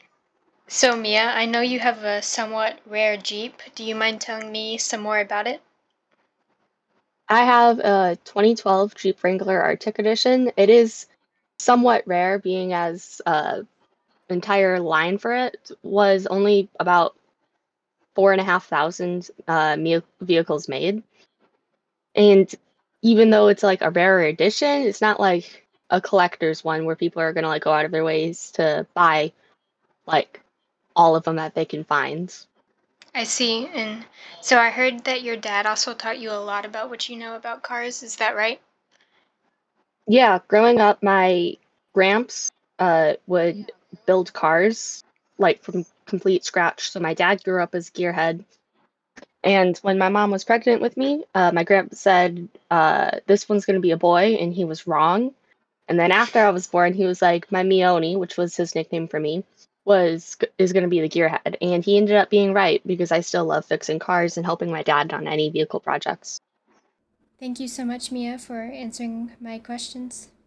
Jeep Interview